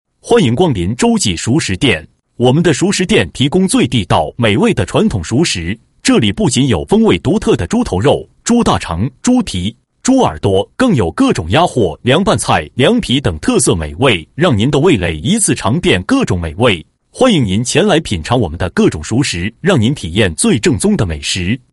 男-053号-列表页